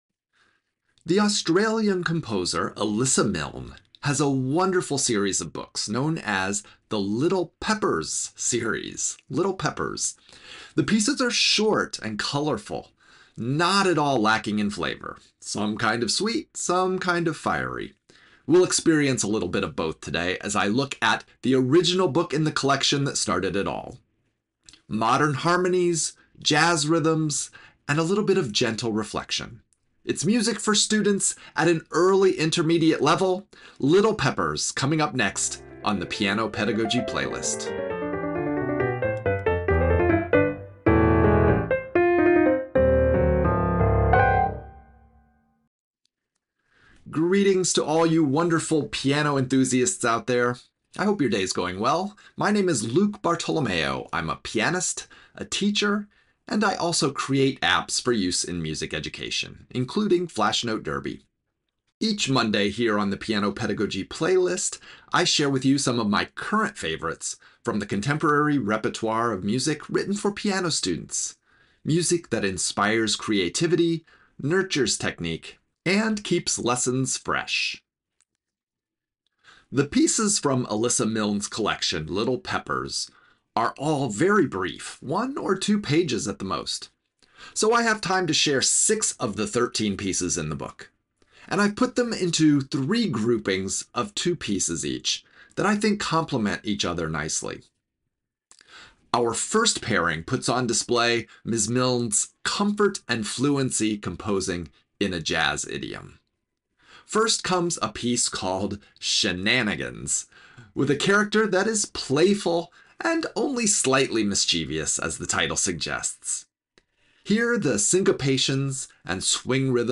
Elissa Milne’s Little Peppers pack jazz flavor, lyricism, and teaching value into little, tasty packages..
I'm playing six short pieces from the collection that may be written for early intermediate students, but are full of rhythmic sophistication, contrasting moods, and vivid character. From jazzy mischief in Shenanigans to the emotional contrasts of Lost and Found, and finally the drive of Deadline leading to the calm of Twilight, each piece shows how much expressive storytelling can fit into a single page of music.